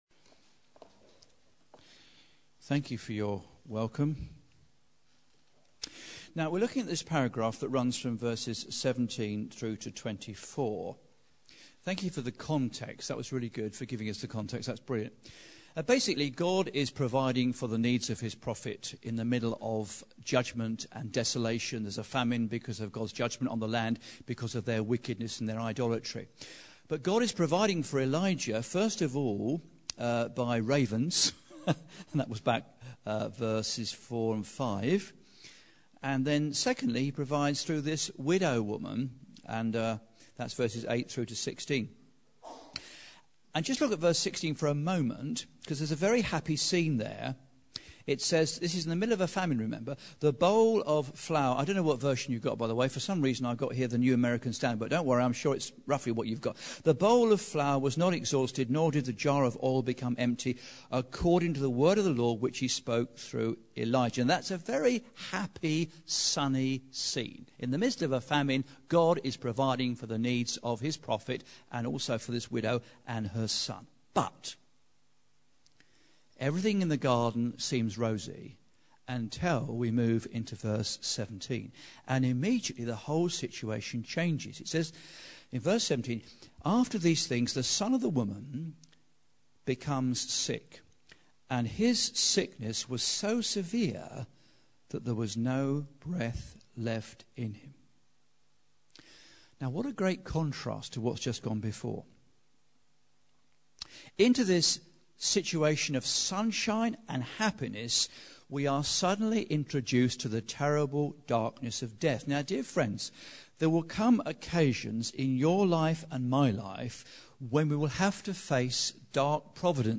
TalkBacks are cafe style seminars with a presentation and opportunities for questions and discussion. What are the definitions, causes and symptoms of depression?